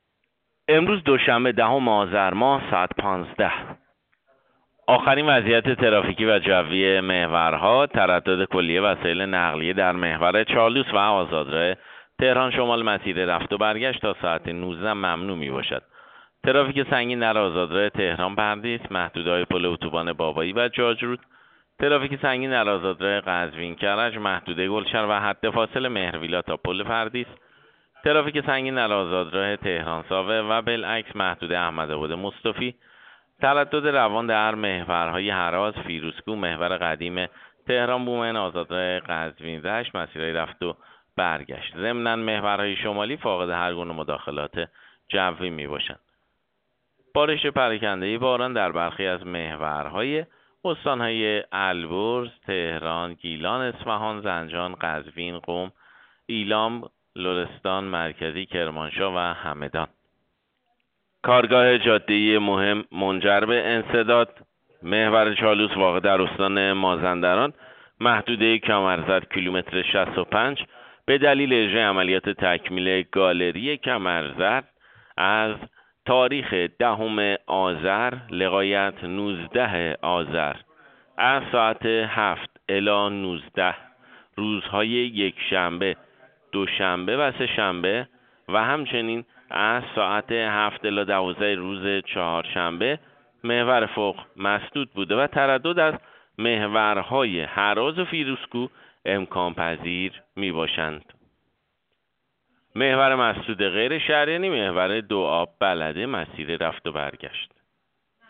گزارش رادیو اینترنتی از آخرین وضعیت ترافیکی جاده‌ها ساعت ۱۵ دهم آذر؛